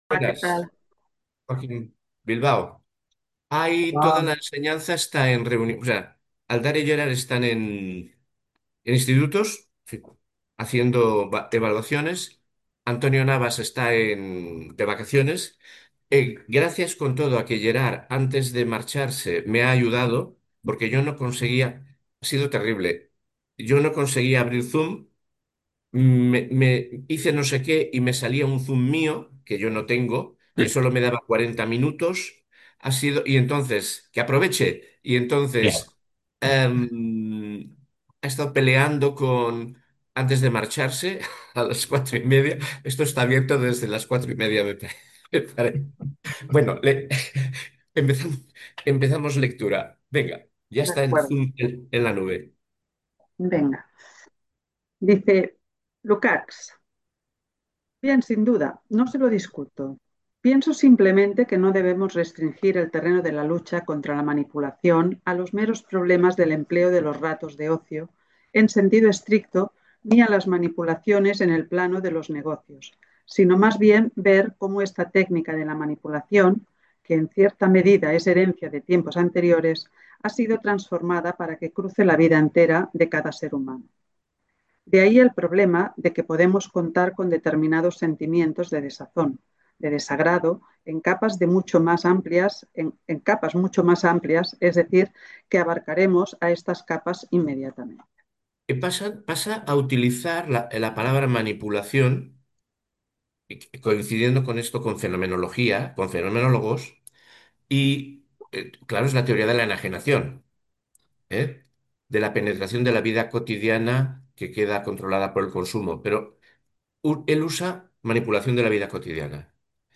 La forma de proceder es leer anticipadamente unas 20 o 25 páginas de texto, que posteriormente son releídas y comentadas en una puesta en común, que dirige un monitor.
En cualquier caso, la grabación del seminario será publicada posteriormente en la web de Espaimarx.